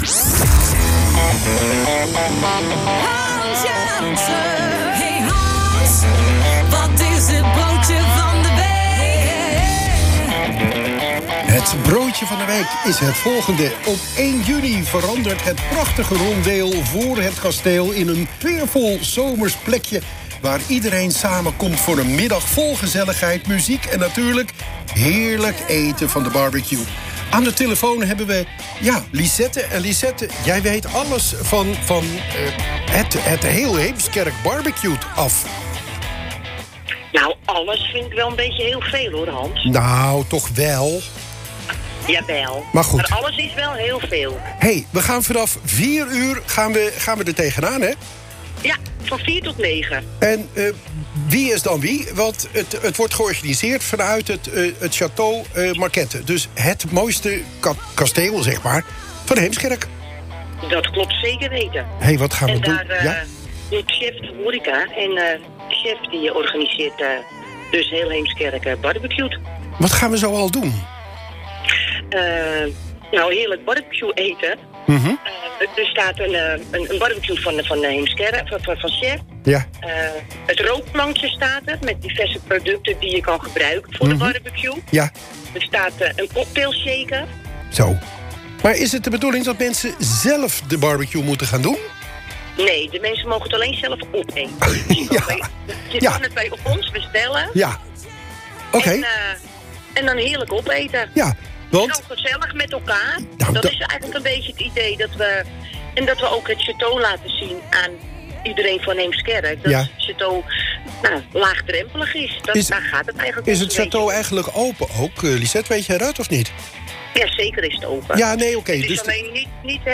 Luister hier het gesprekje